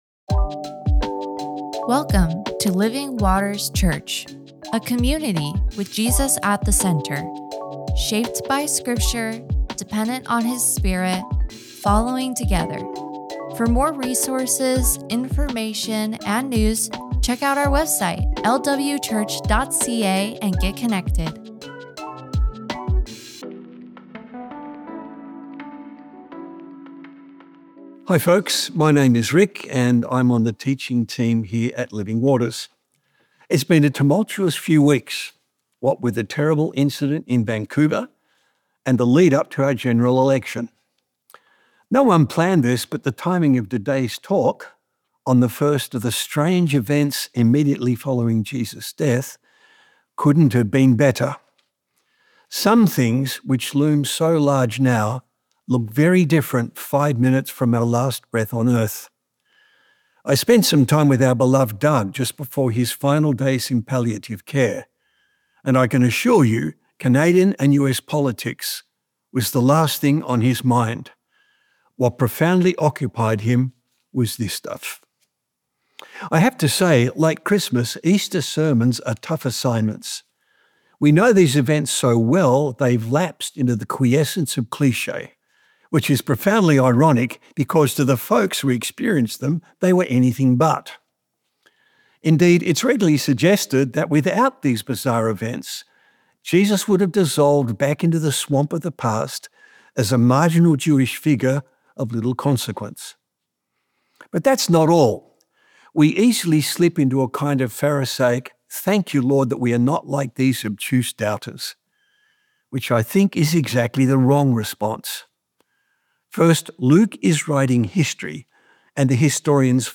This is our third sermon on this passage, so our notes will assume that we are already familiar with the text and the points made in the previous sermons.